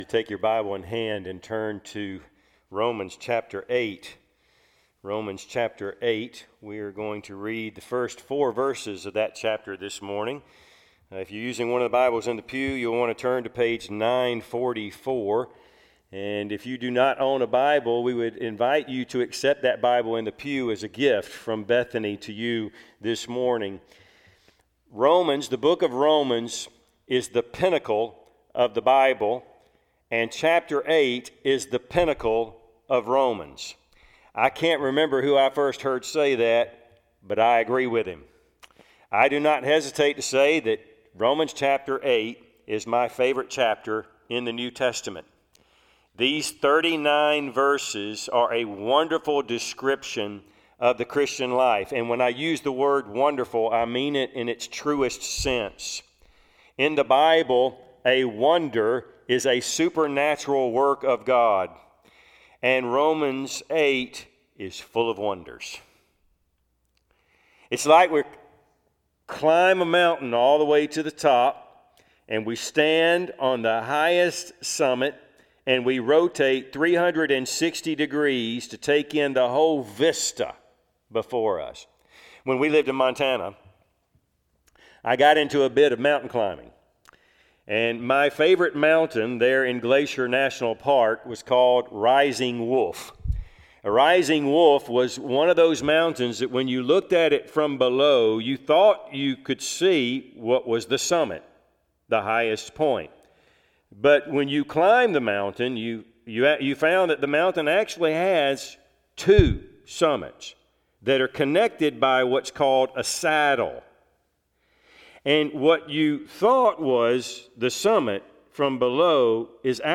Passage: Romans 8:1-4 Service Type: Sunday AM